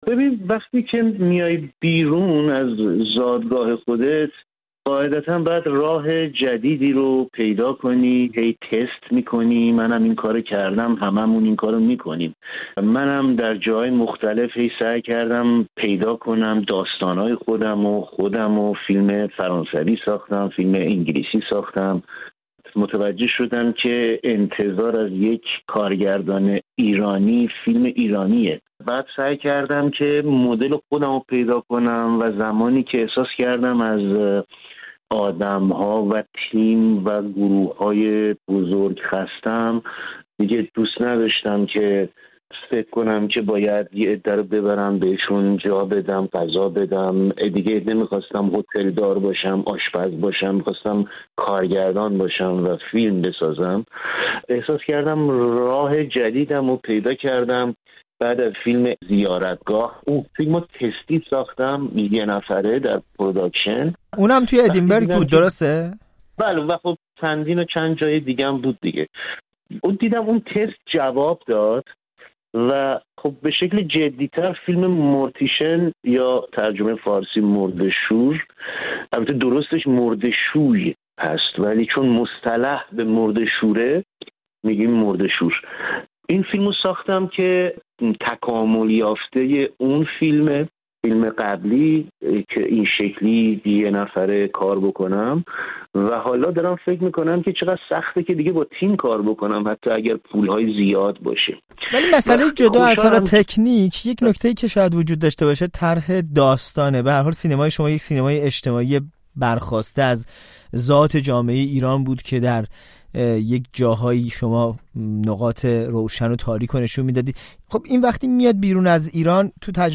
گفت‌وگو با عبدالرضا کاهانی دربارۀ فیلم «مرده‌شور»